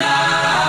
Key-chant_164.1.1.wav